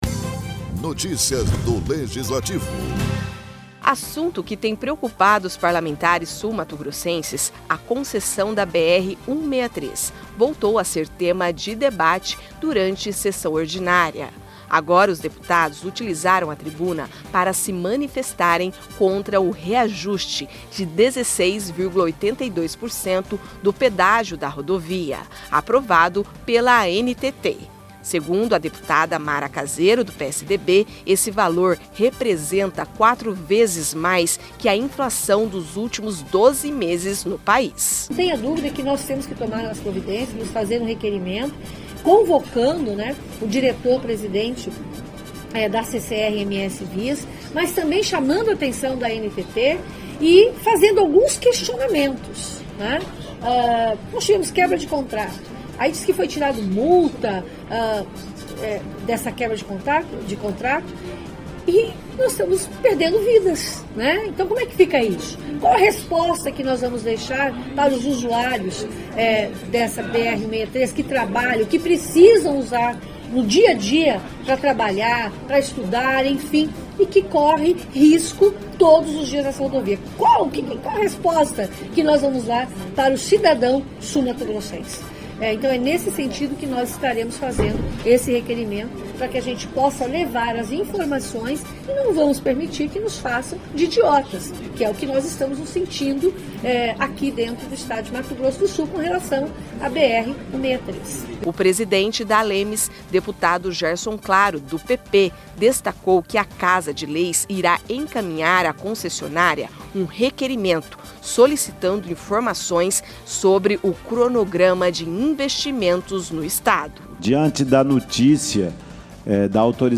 Agora os deputados utilizaram a tribuna para se manifestarem contra o reajuste 16,82% do Pedágio da rodovia, aprovado pela Agência Nacional de Transporte Terrestre (ANTT).